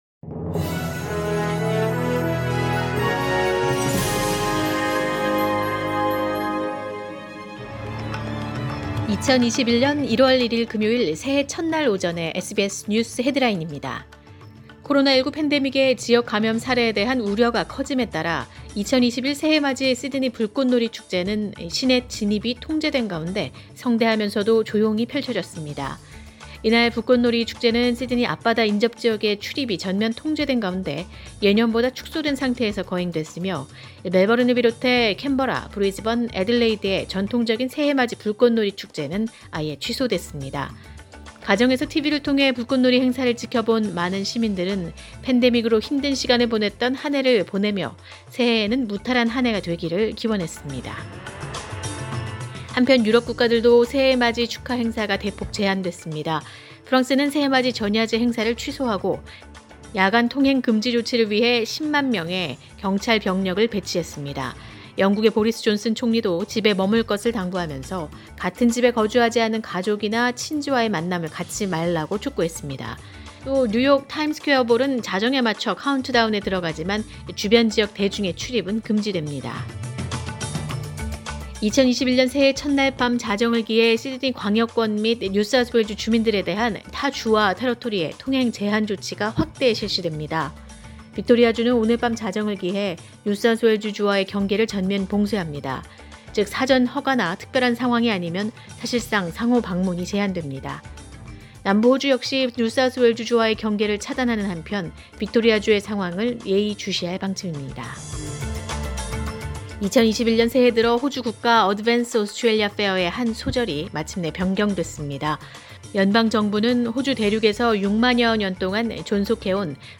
2021년 1월 1일 금요일 새해 첫 날 오전의 SBS 뉴스 헤드라인입니다.